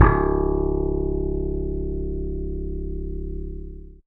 55v-bse01-c#1.aif